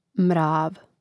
mrȃv mrav